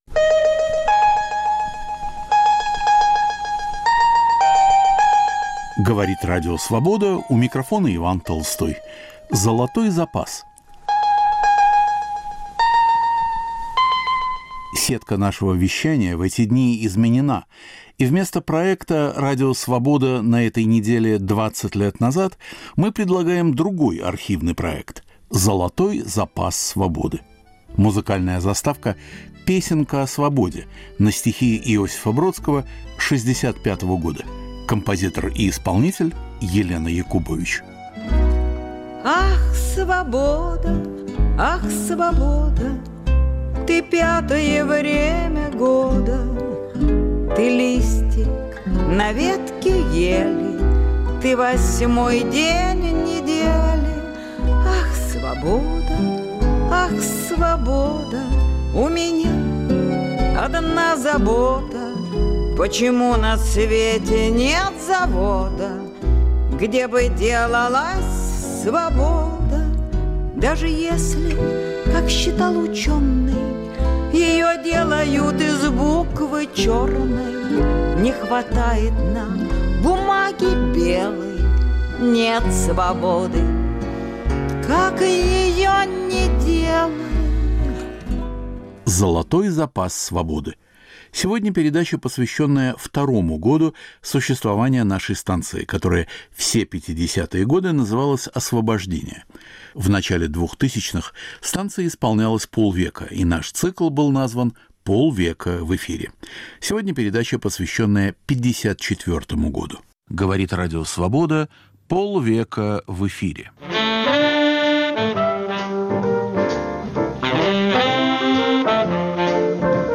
К 50-летию Радио Свобода. Фрагменты передач 1954-го.